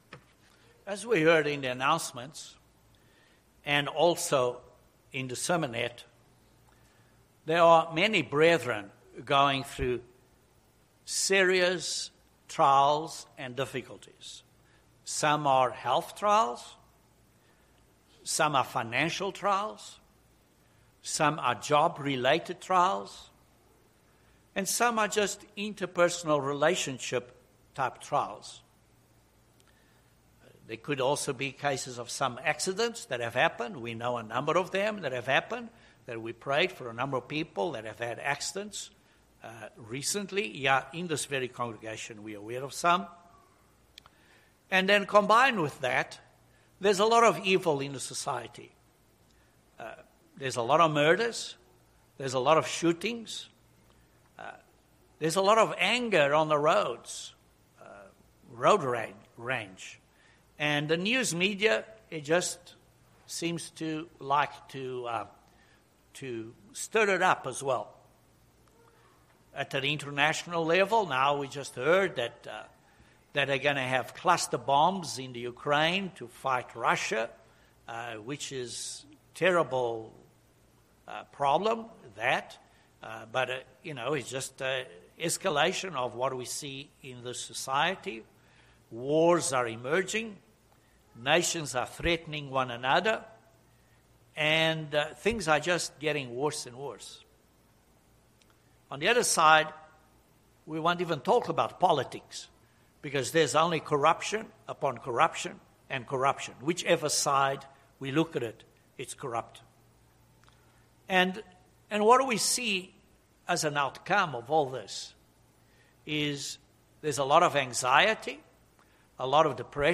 How can we be united as the Father and Jesus Christ are? In this sermon we look at three key principles to be united with one another which are well pleasing to God.